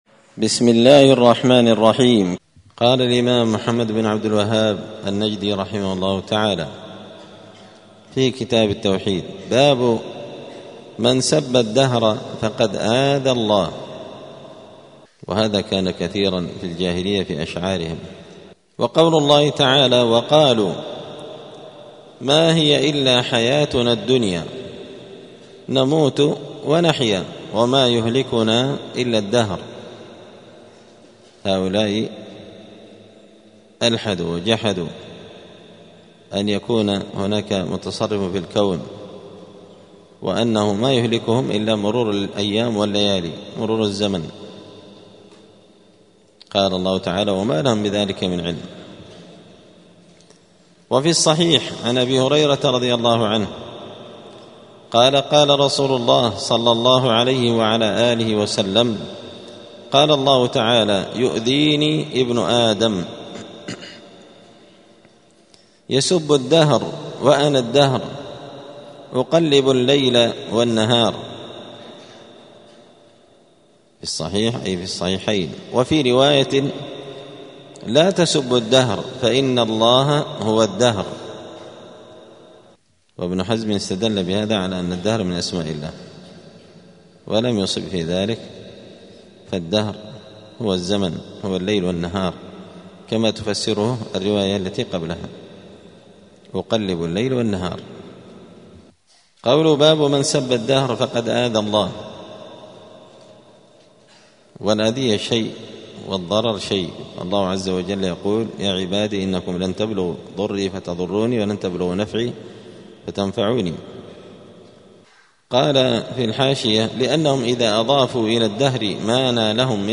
دار الحديث السلفية بمسجد الفرقان قشن المهرة اليمن
*الدرس الرابع والعشرون بعد المائة (124) {باب من سب الدهر فقد آذى الله}*